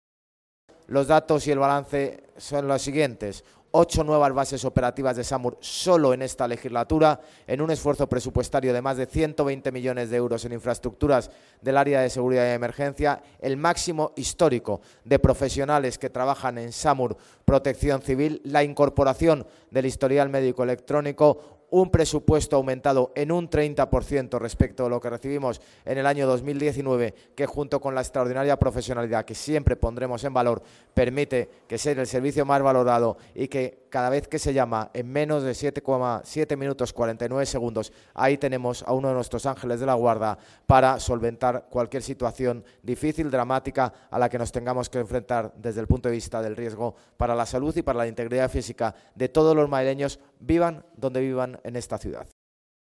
Nueva ventana:Declaraciones del alcalde, José Luis Martínez-Almeida